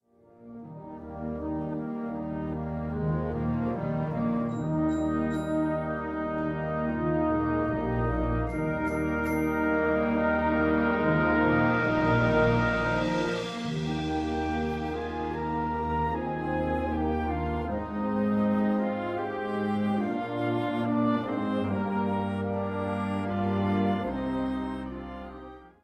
Kategorie Blasorchester/HaFaBra
Unterkategorie Choräle, Balladen, lyrische Musik